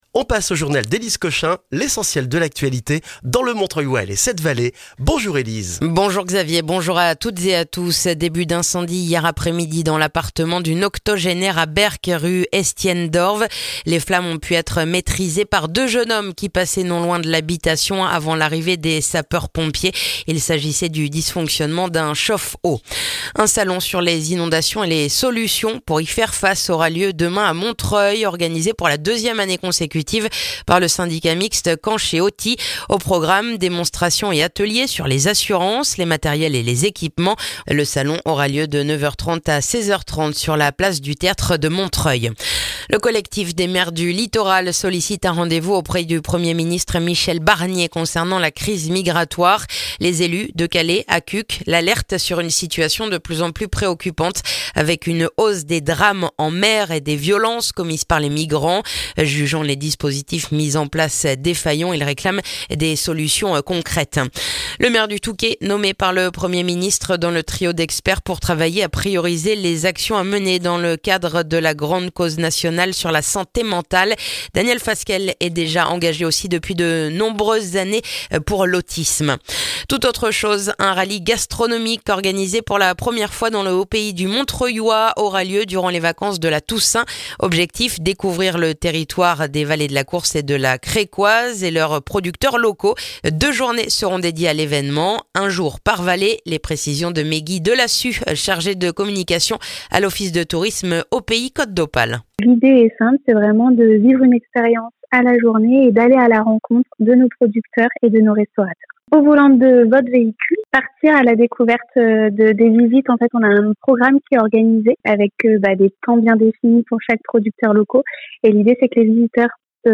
Le journal du vendredi 11 octobre dans le montreuillois